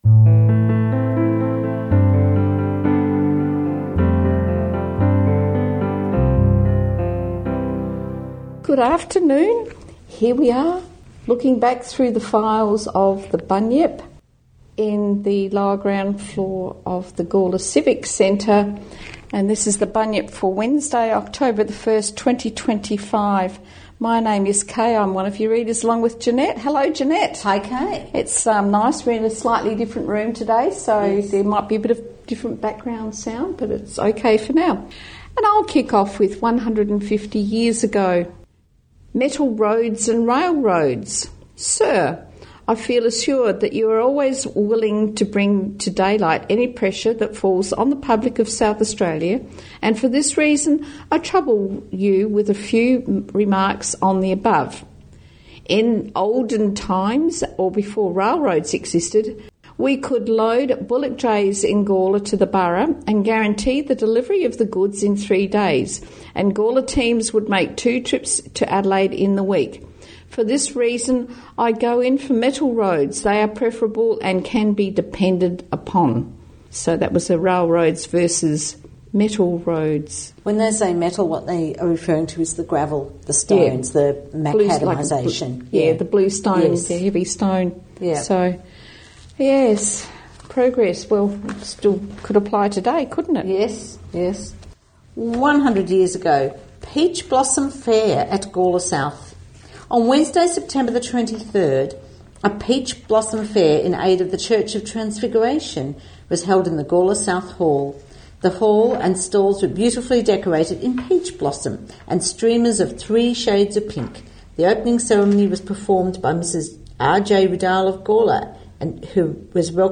Opening and closing music